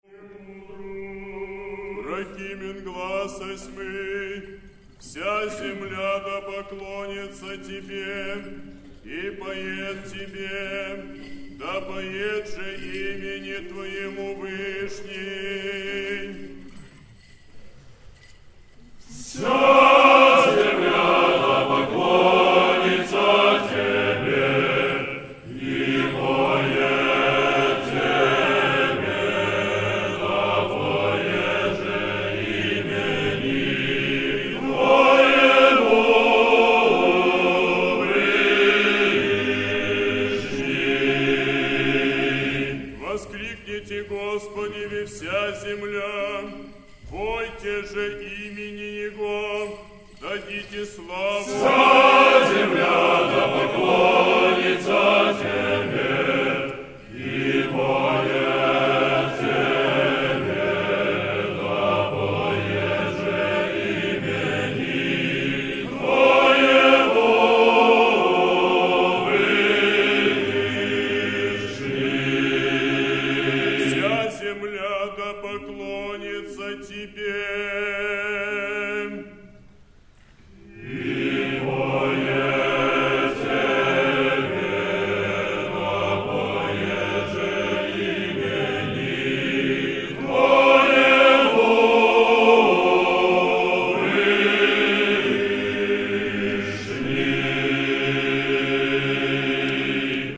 Прокимен в исполнении хора Московского Сретенского монастыря.